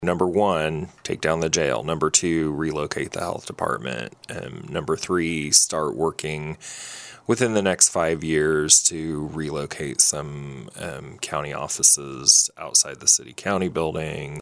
Saline County Administrator Phillip Smith-Hanes joined in on the KSAL Morning News Extra with a look at a number of topics including the destruction of the former Saline County Jail. Smith-Hanes says the price tag to bulldoze the facility is estimated at around $1.2 million dollars.